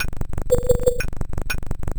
Abstract Rhythm 14.wav